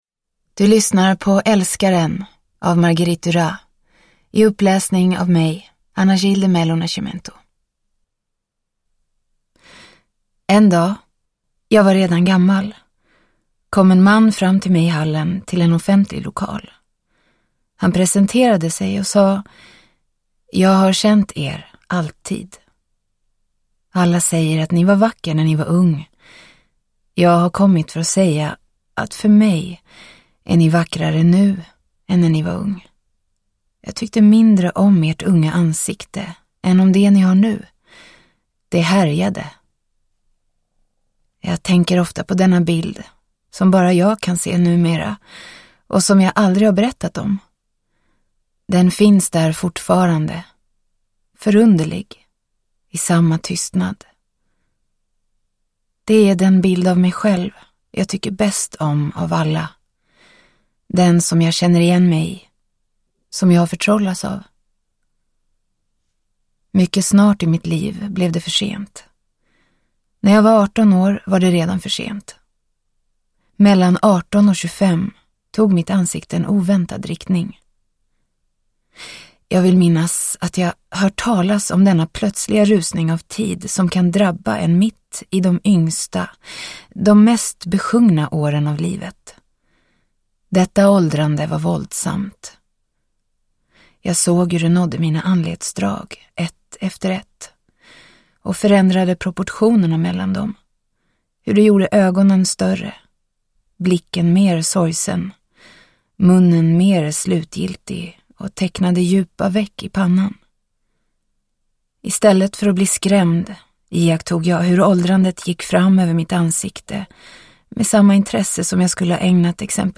Älskaren – Ljudbok – Laddas ner